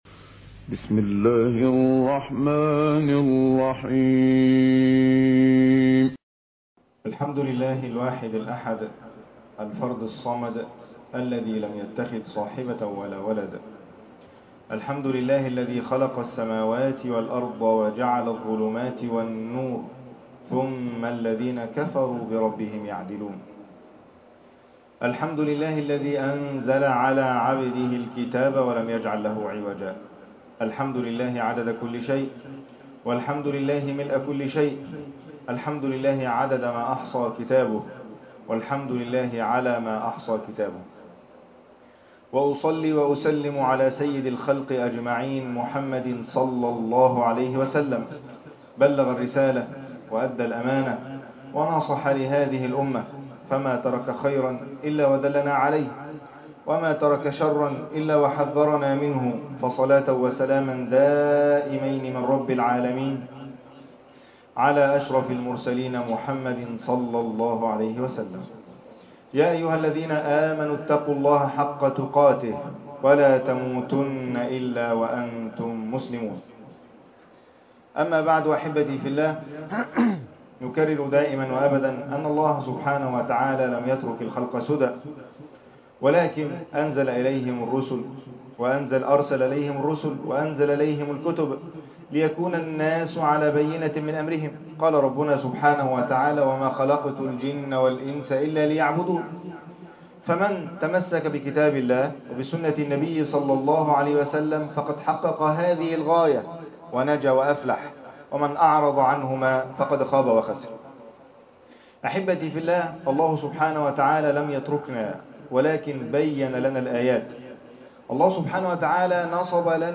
عنوان المادة إنه القرآن - بصائر قرآنية لِطُول البلاء _ خطبة جمعة تاريخ التحميل الثلاثاء 27 يناير 2026 مـ حجم المادة 10.96 ميجا بايت عدد الزيارات 118 زيارة عدد مرات الحفظ 74 مرة مشاهدة المادة حفظ المادة اضف تعليقك أرسل لصديق